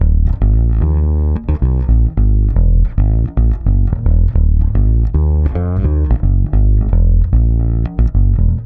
-AL AFRO E.wav